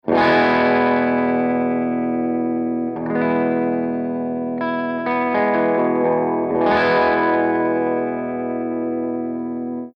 015_AC30_VIBRATO3_P90